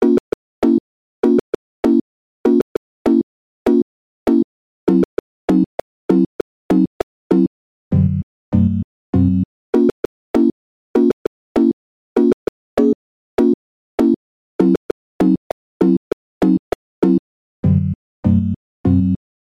spoopy music sketch